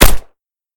val_shoot.ogg